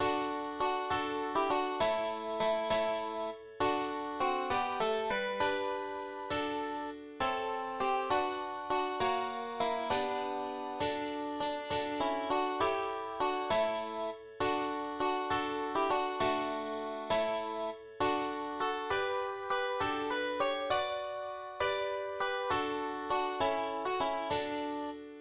unernstes Kunstlied